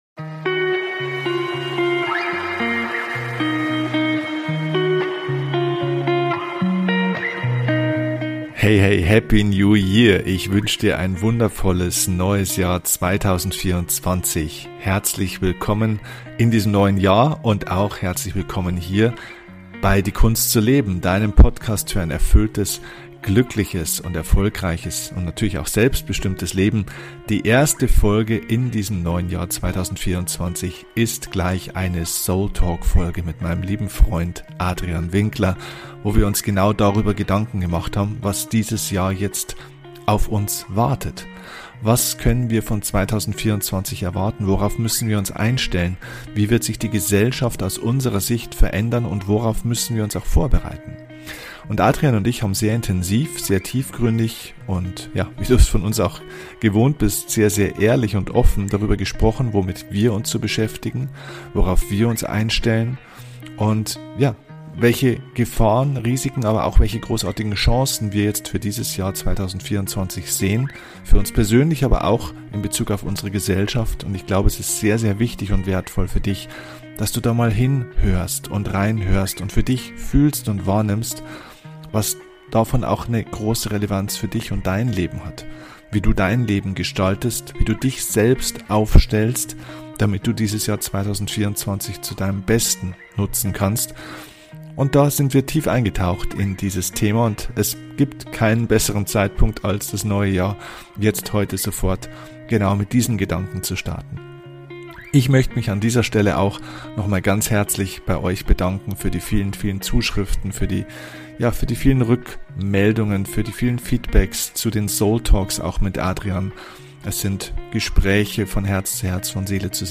Wie immer beim SOULTALK gibt es kein Skript!